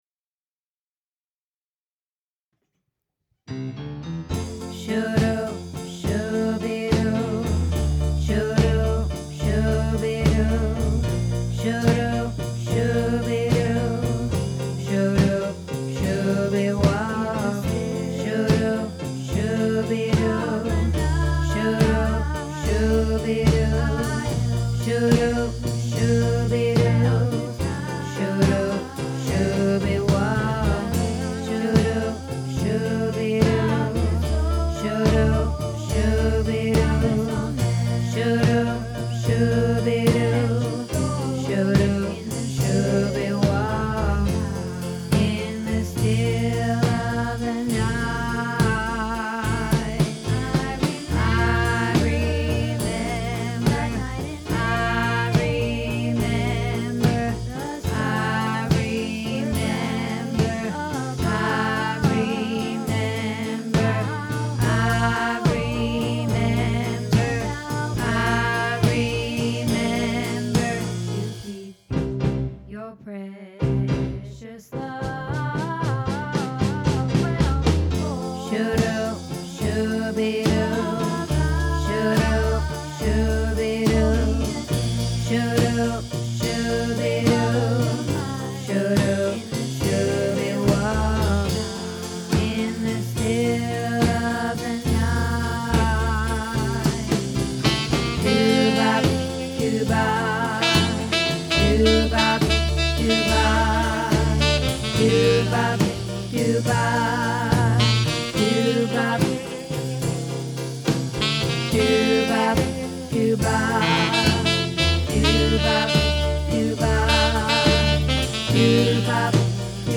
In the Still of the Night - Bass